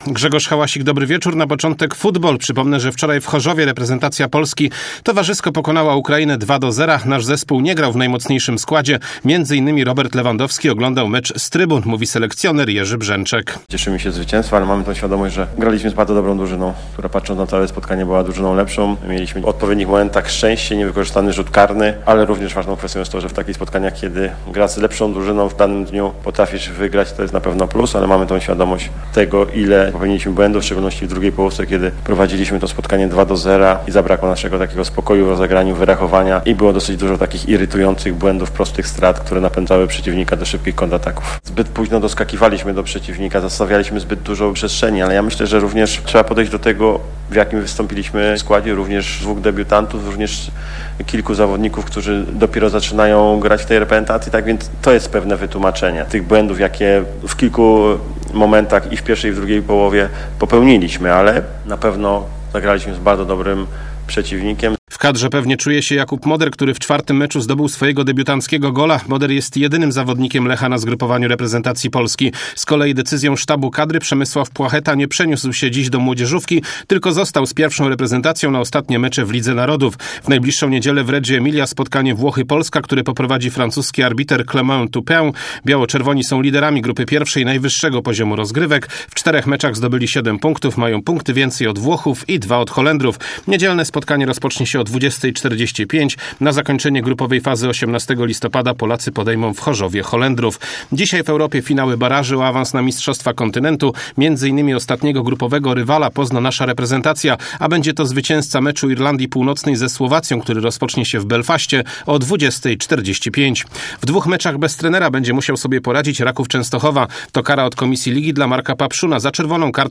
12.11. SERWIS SPORTOWY GODZ. 19:05